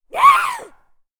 femalezombie_spotted_03.ogg